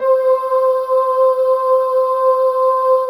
Index of /90_sSampleCDs/USB Soundscan vol.28 - Choir Acoustic & Synth [AKAI] 1CD/Partition C/13-MARJOLIE